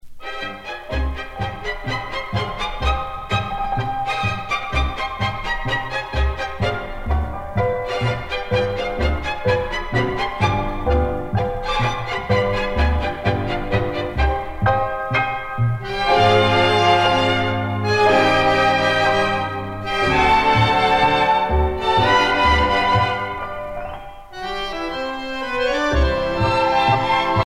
danse : tango
Pièce musicale éditée